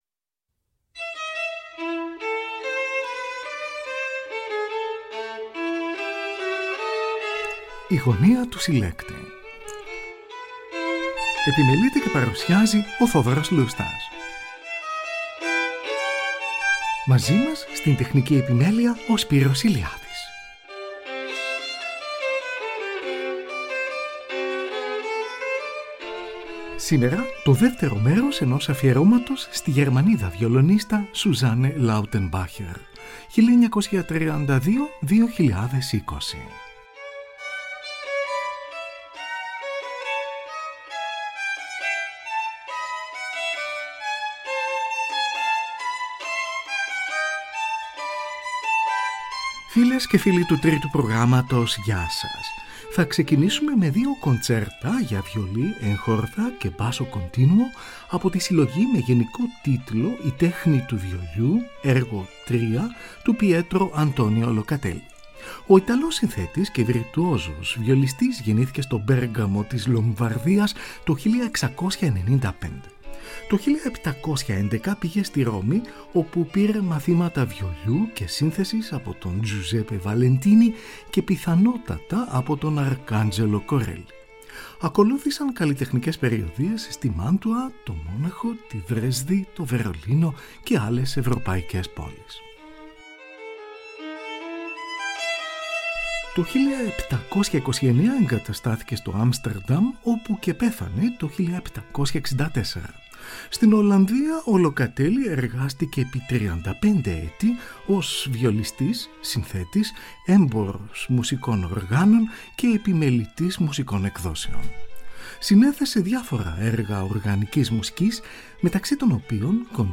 κοντσέρτα για βιολί, έγχορδα και basso continuo